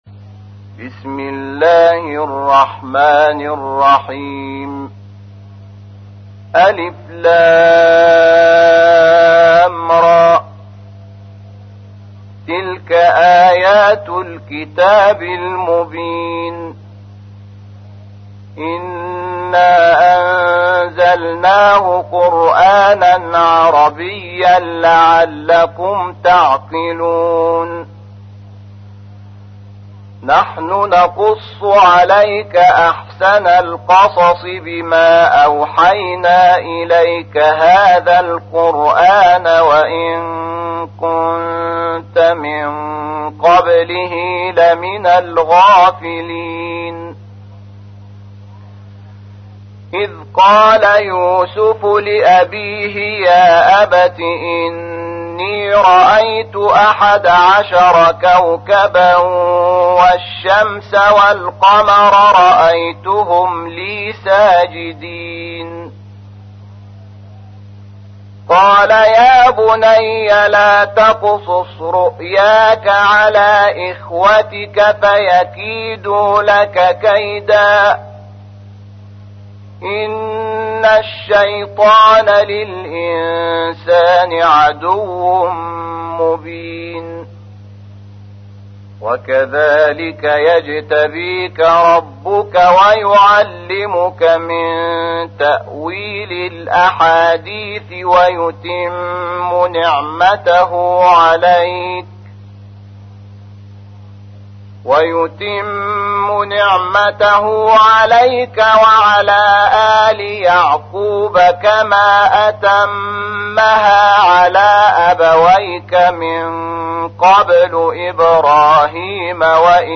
تحميل : 12. سورة يوسف / القارئ شحات محمد انور / القرآن الكريم / موقع يا حسين